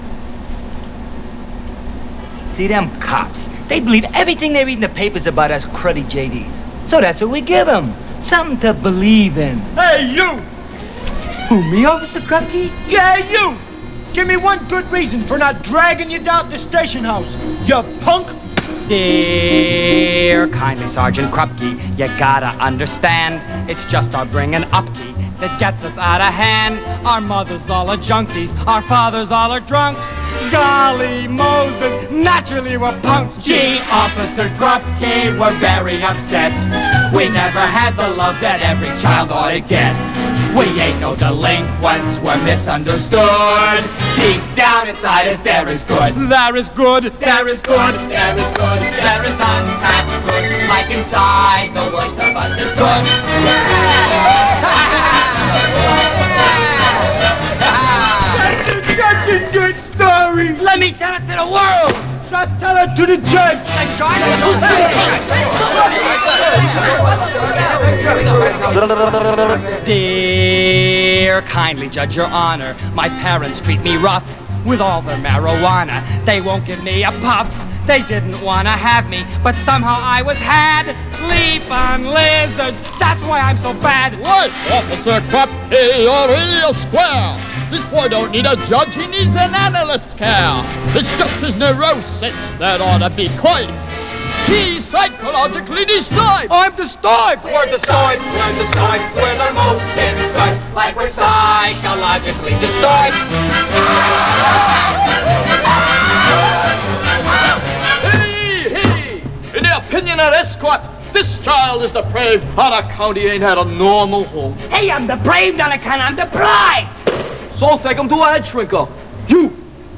The following song